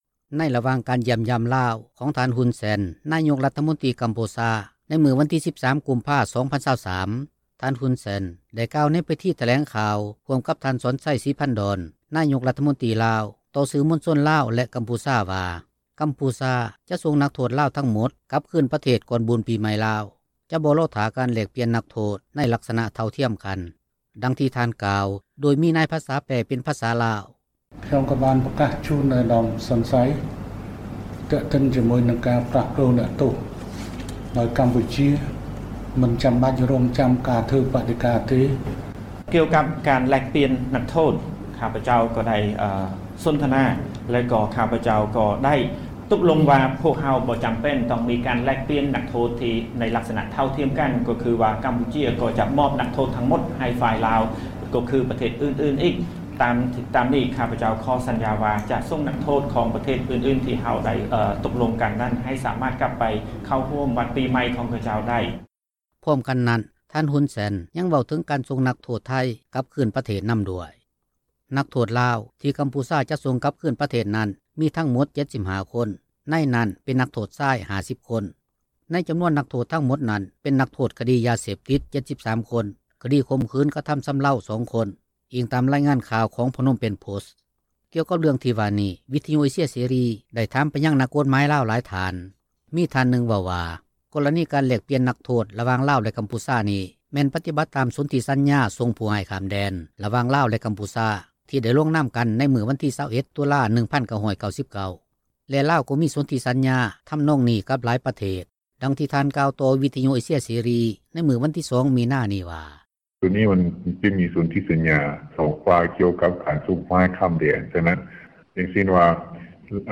ດັ່ງທີ່ທ່ານກ່າວ ໂດຍມີນາຍພາສາ ແປເປັນພາສາລາວ.